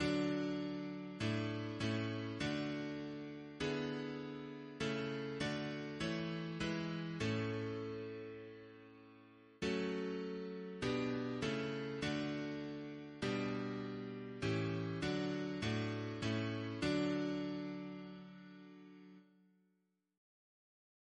Double chant in D